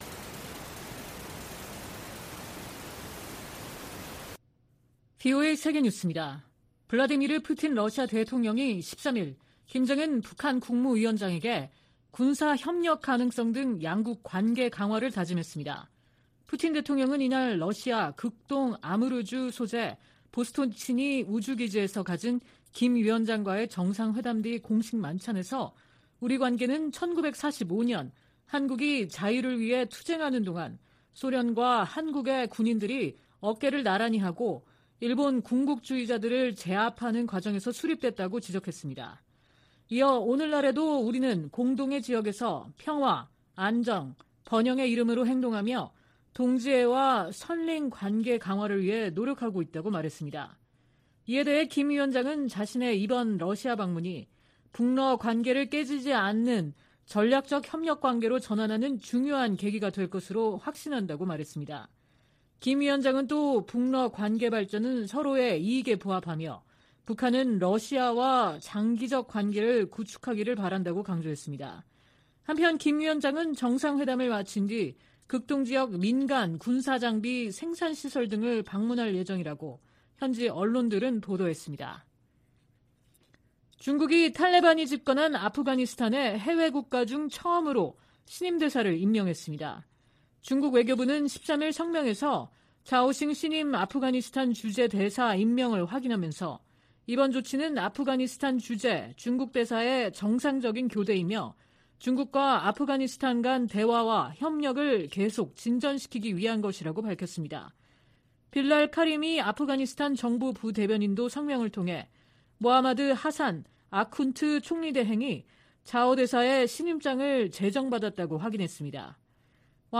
VOA 한국어 '출발 뉴스 쇼', 2023년 9월 14일 방송입니다. 김정은 북한 국무위원장과 블라디미르 푸틴 러시아 대통령의 회담이 현지 시간 13일 오후 러시아 극동 우주기지에서 열렸습니다. 직전 북한은 단거리 탄도미사일 두 발을 동해상으로 발사했습니다. 미국 정부는 북한과 러시아의 무기거래에 거듭 우려를 나타내며 실제 거래가 이뤄지면 추가 행동에 나서겠다고 강조했습니다.